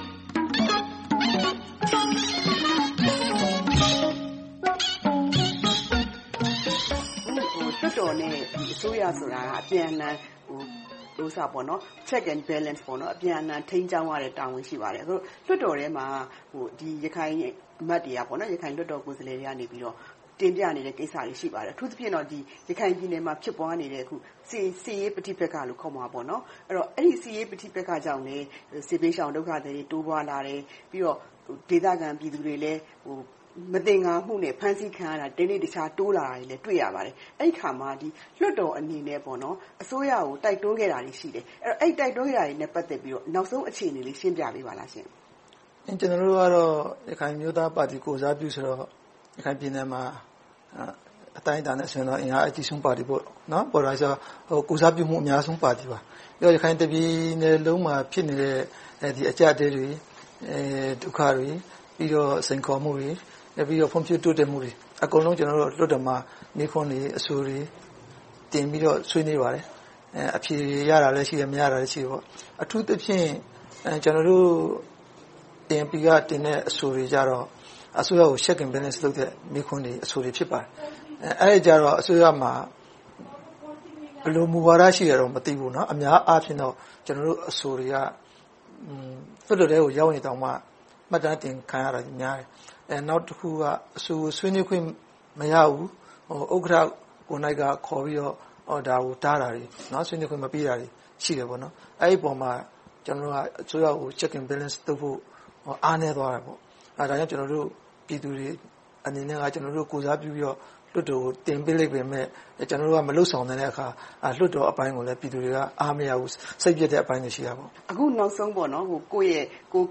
သီးသန့်တွေ့ဆုံမေးမြန်းထားပါတယ်။